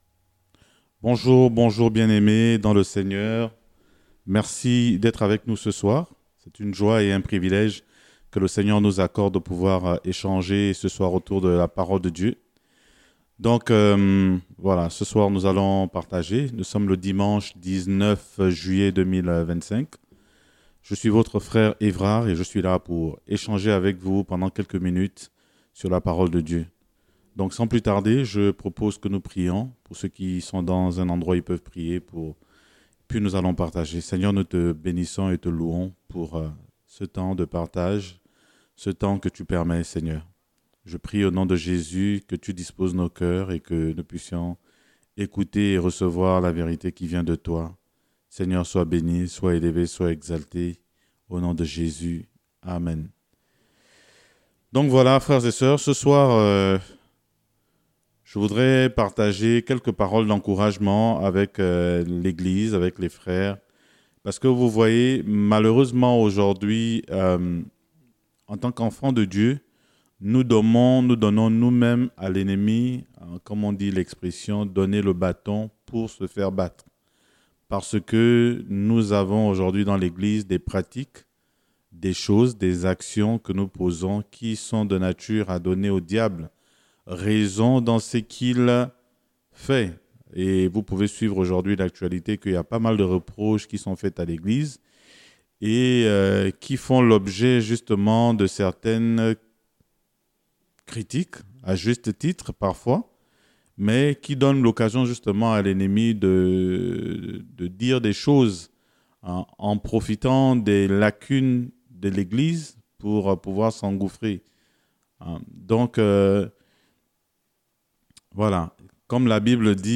Enseignement